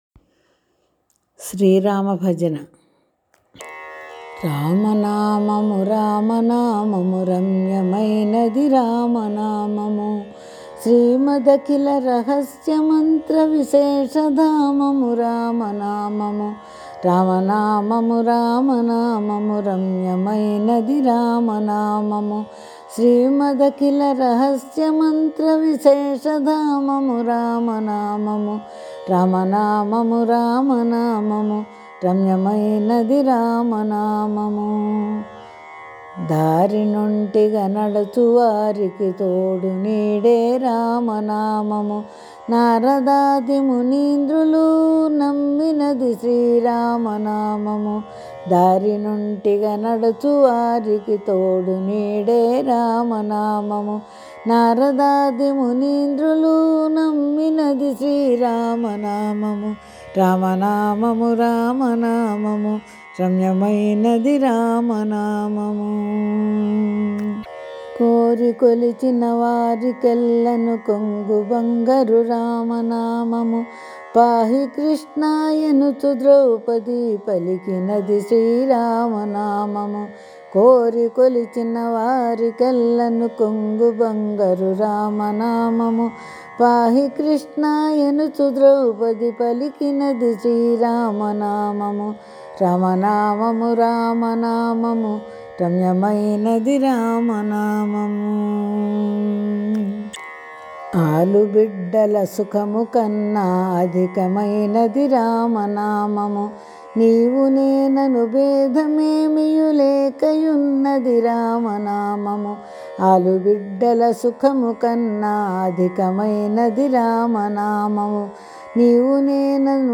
భజన పాటలు
1 శ్రీ రామ భజన:-